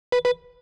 Beep-sound-for-watermarking.wav